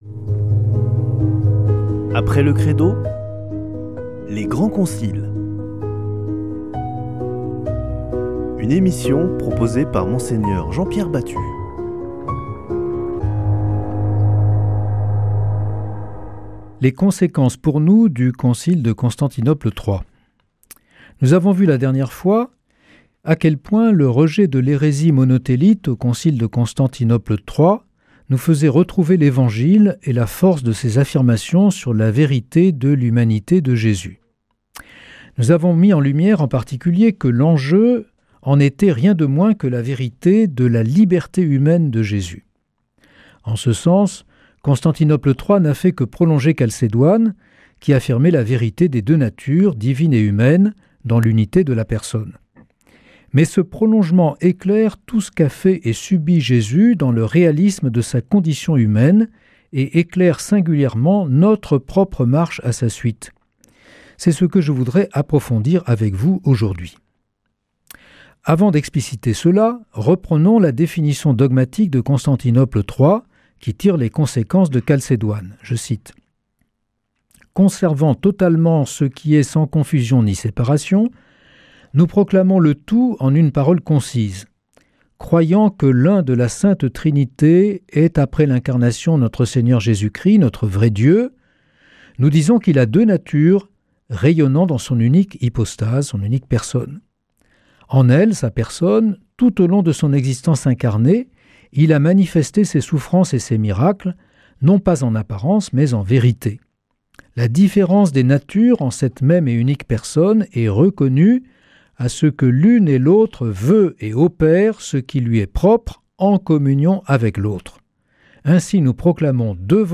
Une émission présentée par Mgr Jean-Pierre Batut